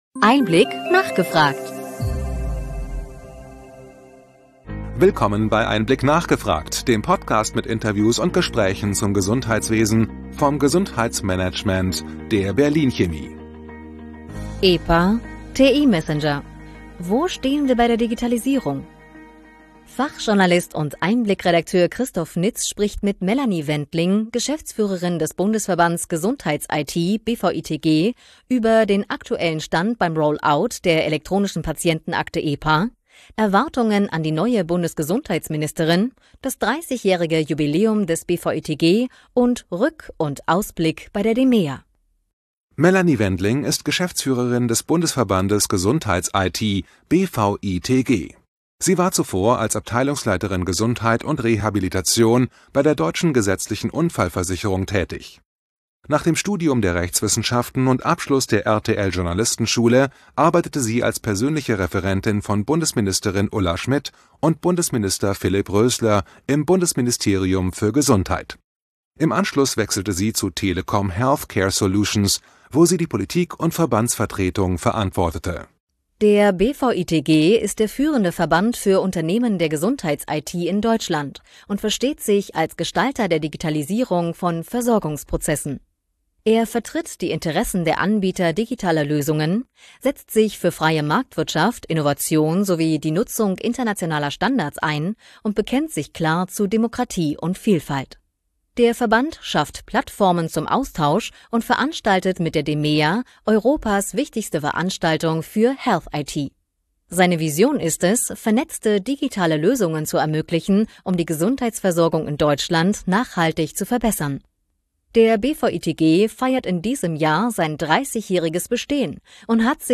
EinBlick – nachgefragt Podcast mit Interviews und Diskussionsrunden mit Expert:innen des Gesundheitswesens e-PA, TI-Messenger.